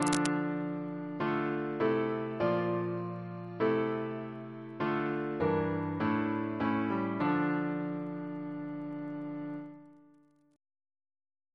Single chant in D minor Composer: John Harrison (1808-1871) Reference psalters: ACP: 134; CWP: 14; PP/SNCB: 84; RSCM: 180